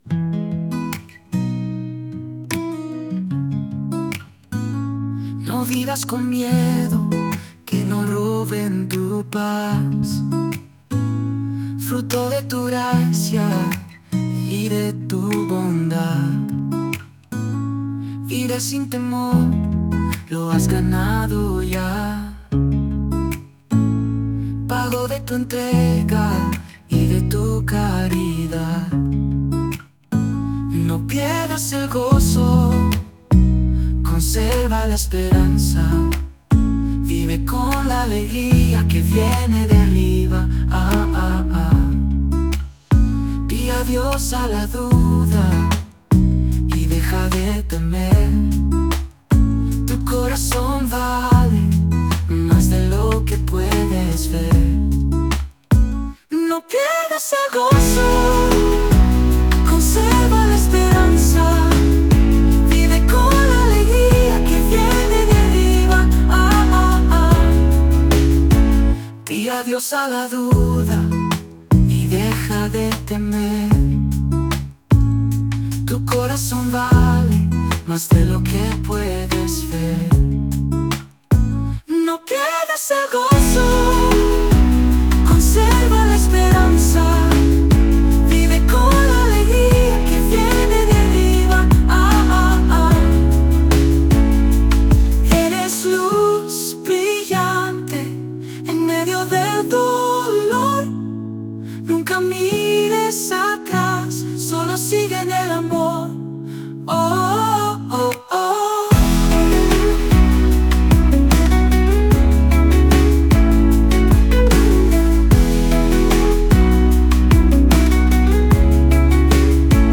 Latino